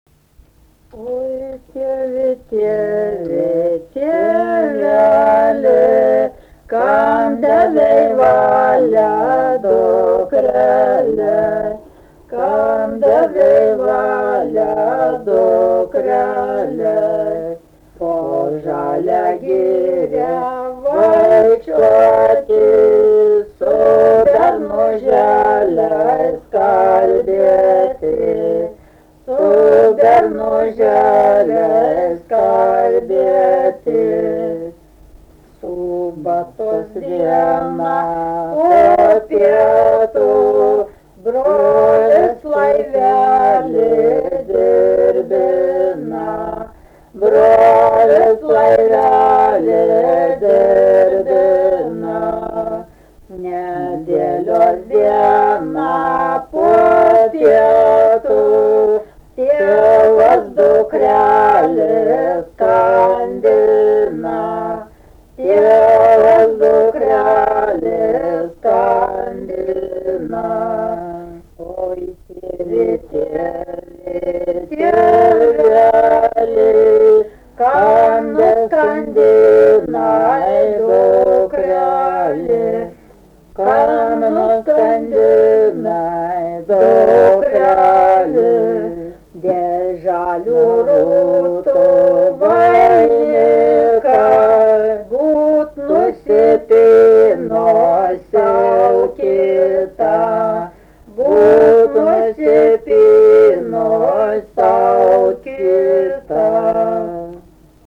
Vestuvių maršas keltuvių rytą
Dalykas, tema šokis
Erdvinė aprėptis Šilai (Telšiai)
Atlikimo pubūdis instrumentinis